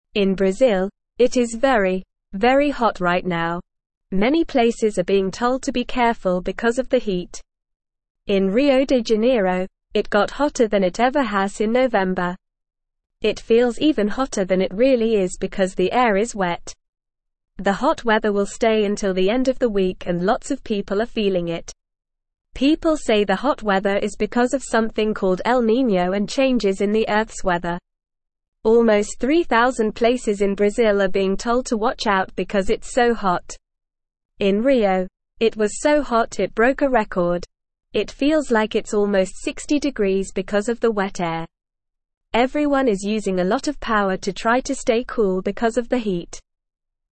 Normal
English-Newsroom-Beginner-NORMAL-Reading-Hot-Weather-Alert-Brazil-Experiencing-Record-Breaking-Heat.mp3